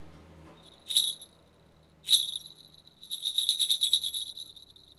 マイクロフォンは、SONYのワンポイントステレオ録音用、ECM-MS907を使いました。
5 これは、手に持ってシャンシャン鳴らすスズです。
”しゃん、しゃん、しゃらしゃらしゃらしゃら”ってな感じに振ってみました。
hand-bell1.wav